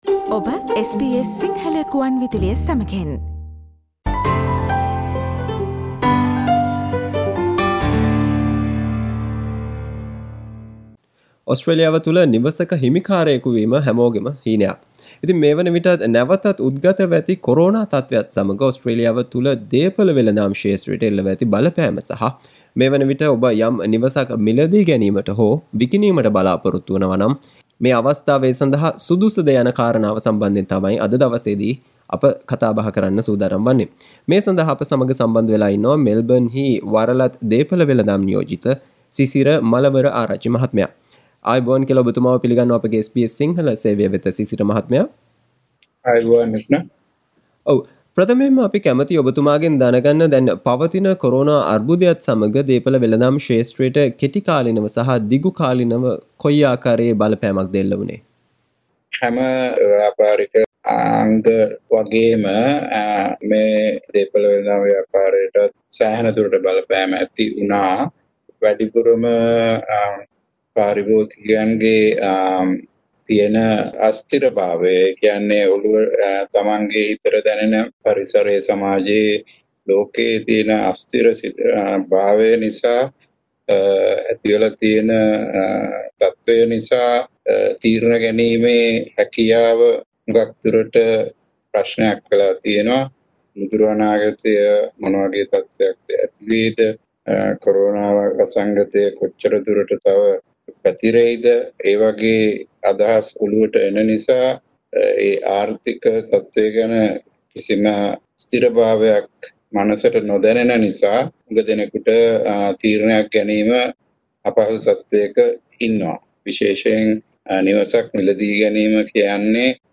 SBS Sinhala Interview